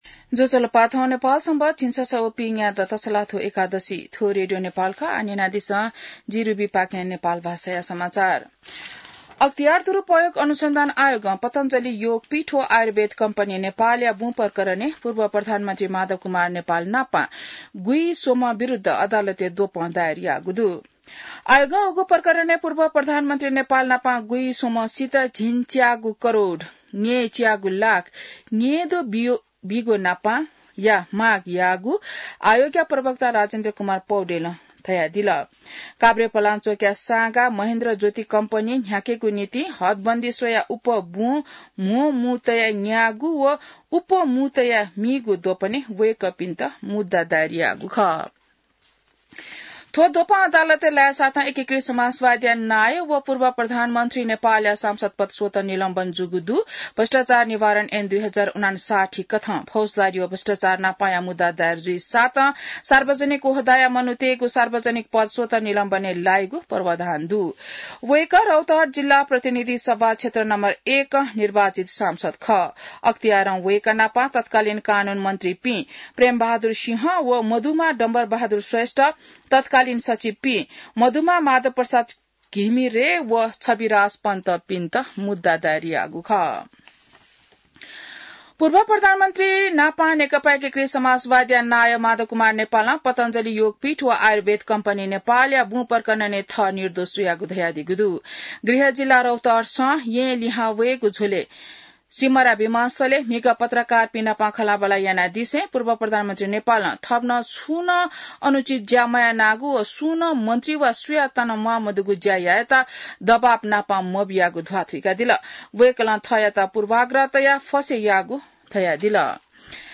नेपाल भाषामा समाचार : २३ जेठ , २०८२